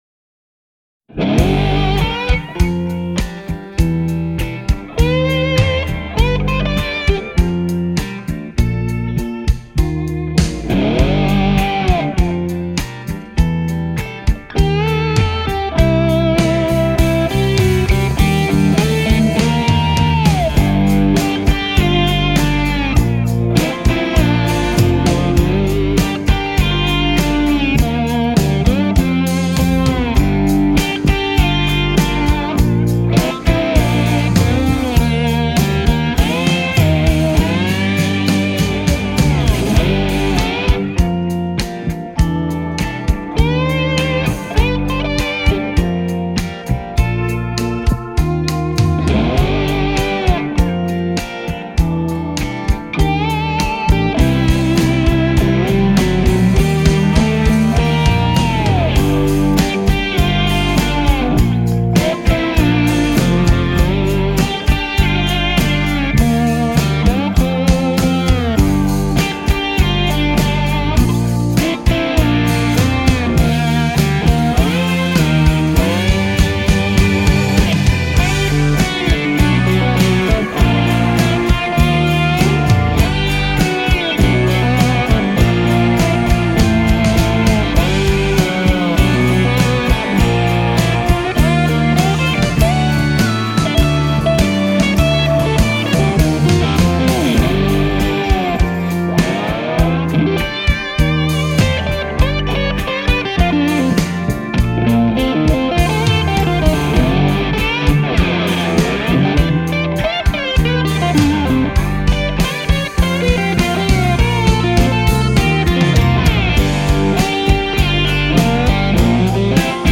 Genre: Blues, Louisiana Blues, Electric Blues Guitar